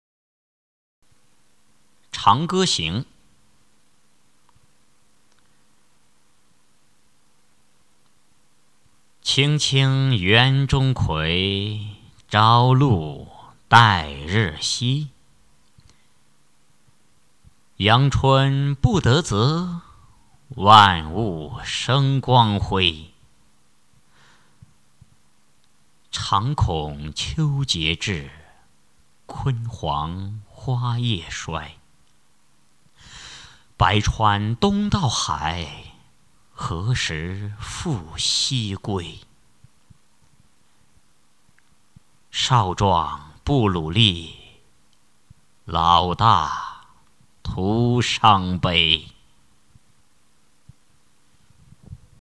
《长歌行》原文、译文、赏析（含mp3朗读）